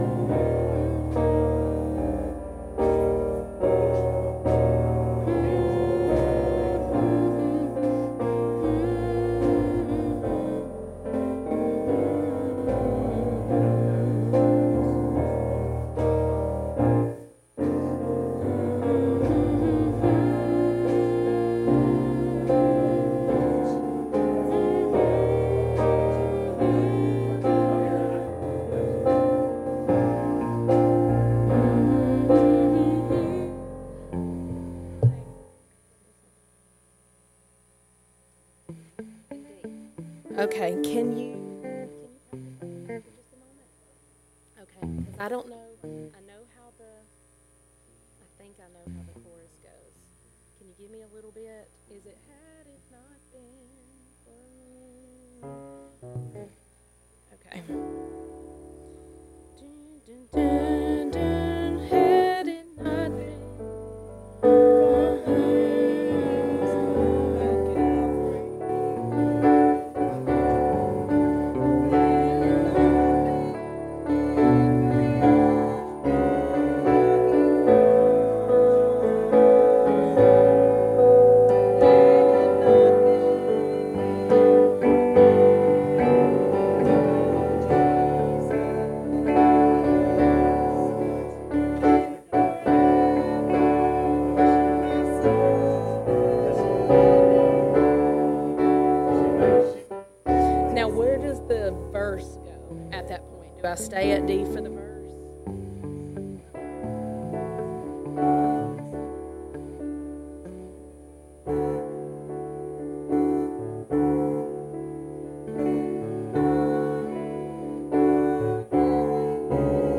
Service Type: Sunday Evening Services